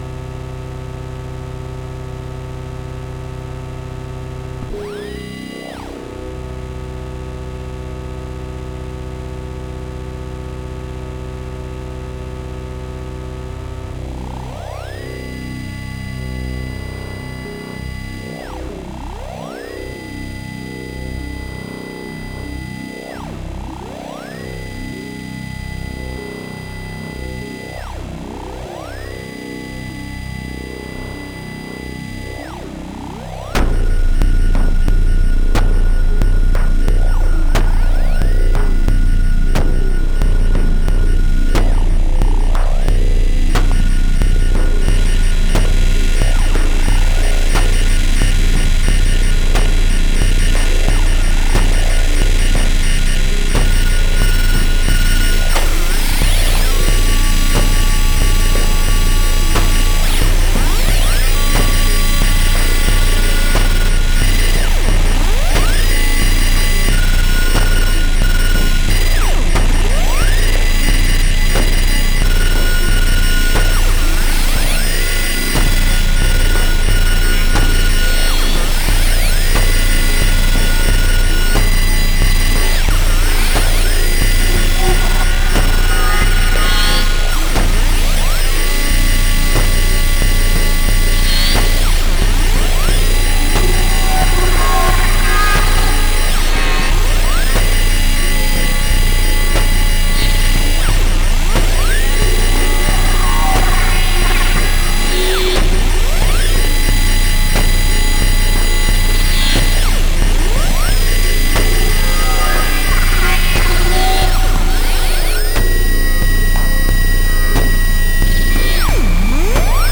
Кто занимается музыкой с использованием модульных синтезаторов?
Чистоты я стараюсь избегать в своём творчестве, допускается лишь некоторая оптимизация в названиях композиций.